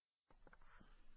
Prayer and Worship Service
No sermon this week, as we gathered for an extended time of worship and prayer.